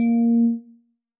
Techmino/media/sample/bell/2.ogg at fd3910fe143a927c71fbb5d31105d8dcaa0ba4b5
添加三个简单乐器采样包并加载（之后用于替换部分音效）